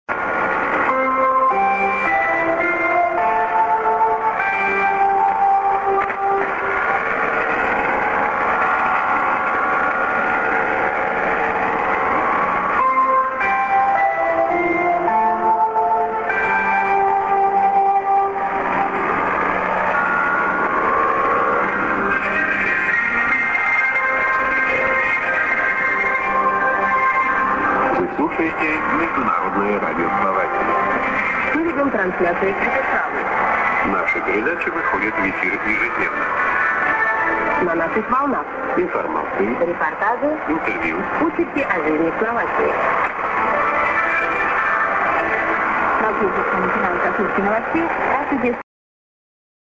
IS->ID(man+women)->　＊西アジア向けで良好